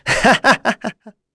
Lucias-vox-Happy2.wav